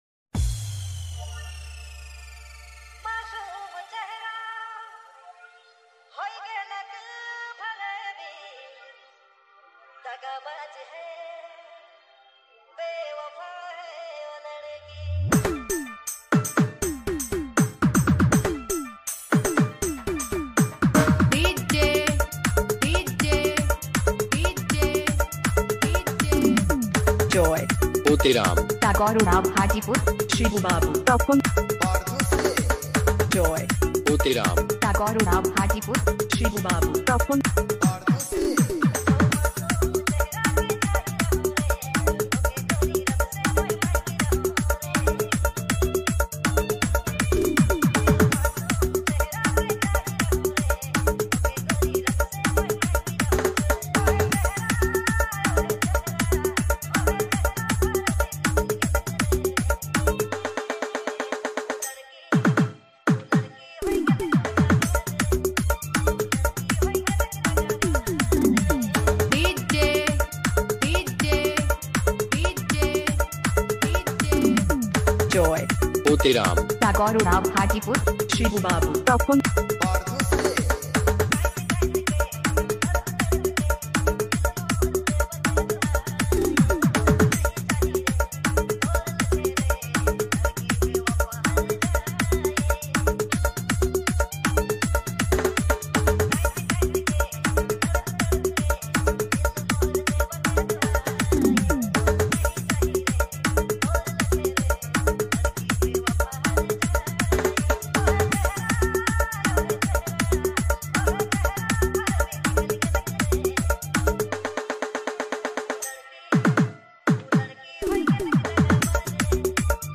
Dj Remixer